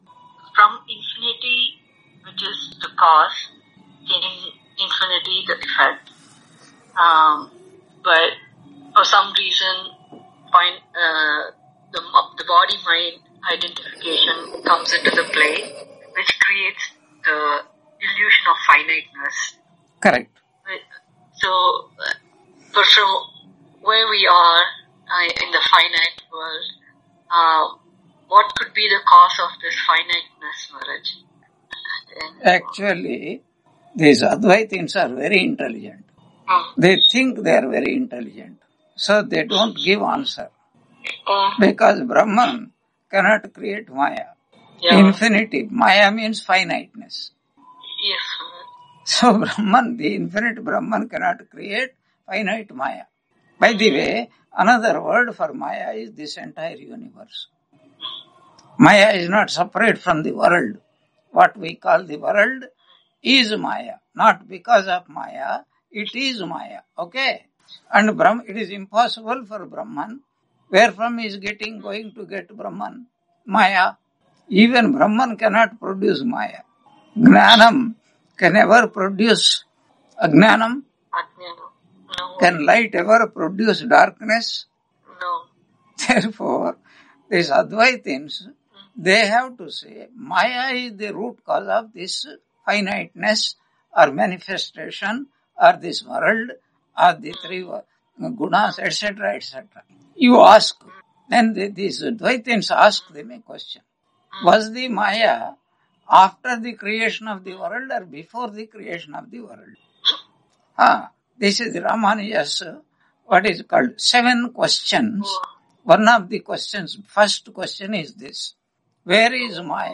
Brihadaranyaka Upanishad Santhi Mantra Lecture 08 on 15 February 2026 Q&A - Wiki Vedanta